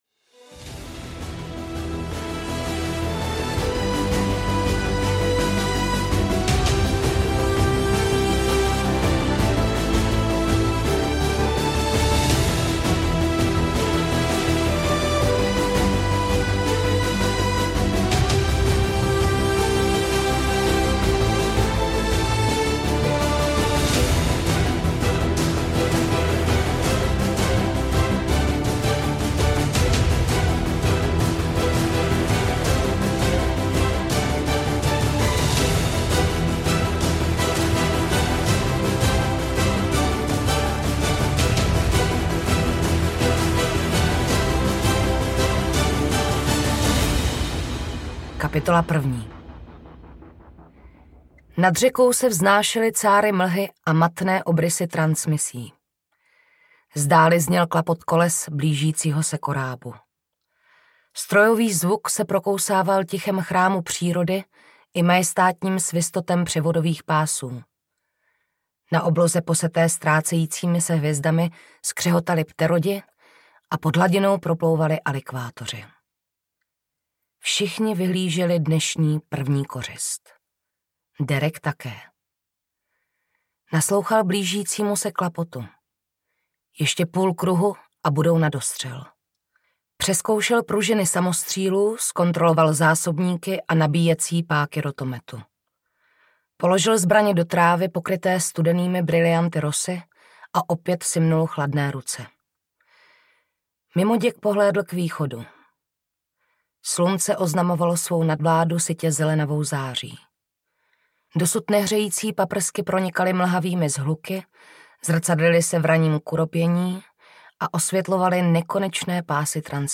Legenda o hadech a ohni (Svět transmise) audiokniha
Ukázka z knihy